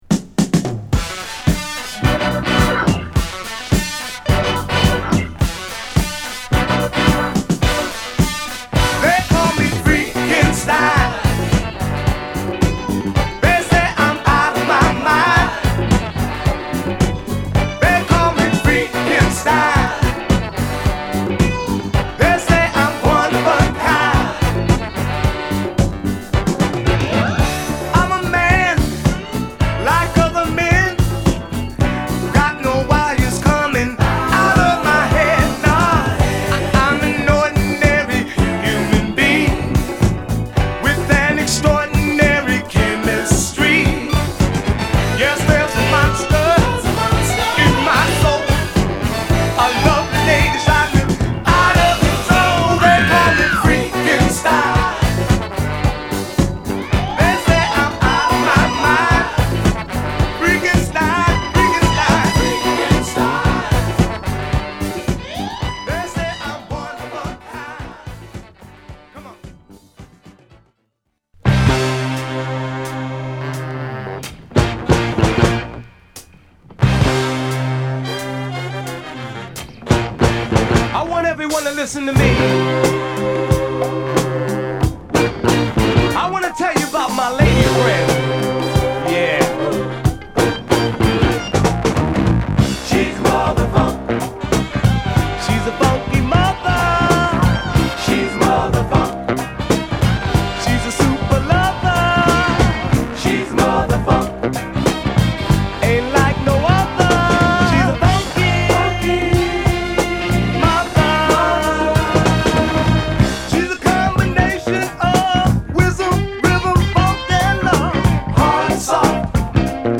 フィラデルフィア発のヴォーカル・グループ